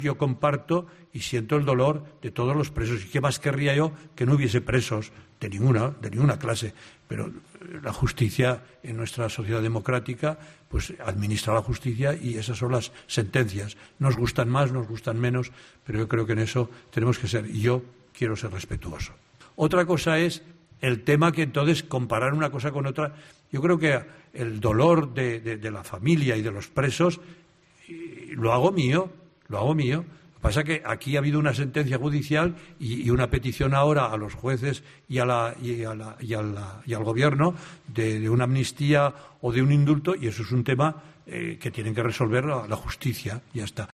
Palabras que ha pronunciado este martes durante la rueda de prensa celebrada con motivo de la presentación de un nuevo obispo auxiliar de Barcelona, Don Javier Vilanova.